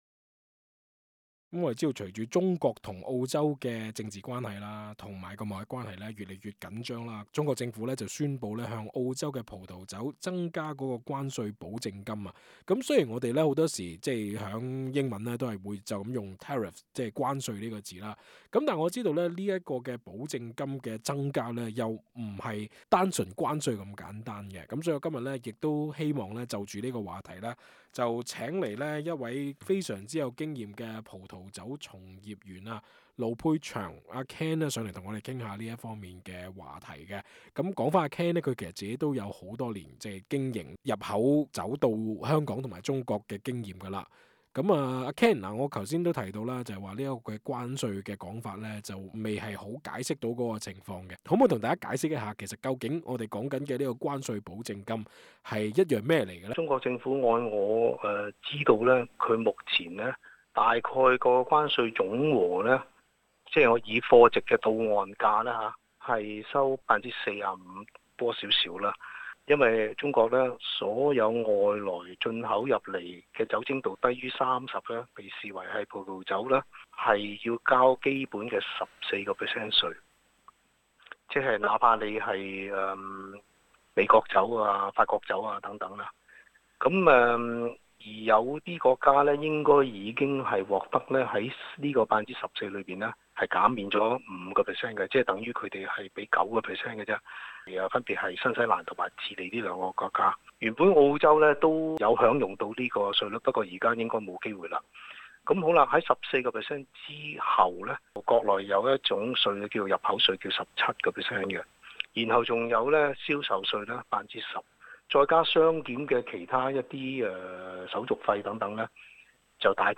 欲知更多詳情，請收聽本台足本訪問。